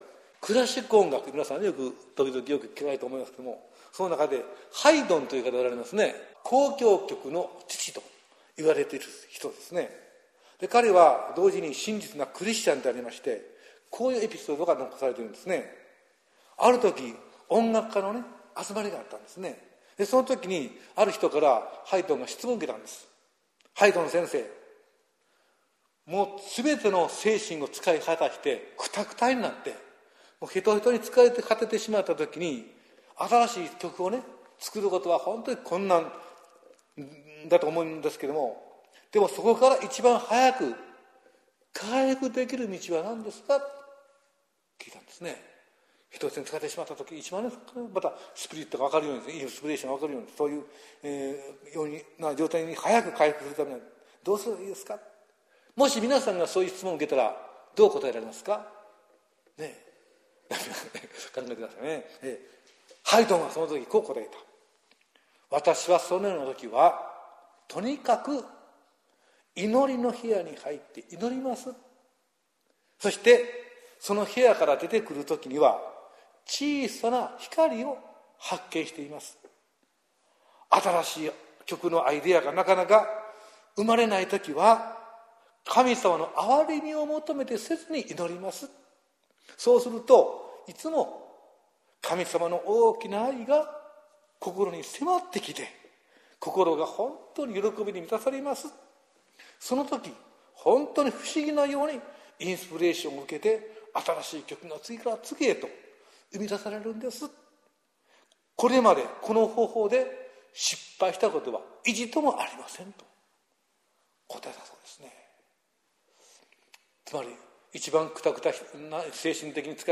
説教集 | あお福音ルーテル教会
あお福音ルーテル教会の礼拝説教を音声で公開しております。